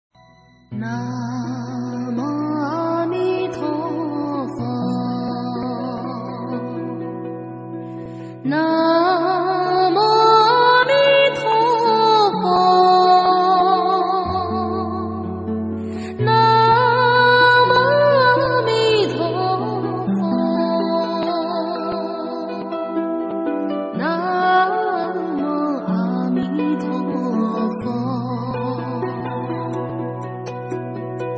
缥缈的仙音，听后你是什么感觉呢！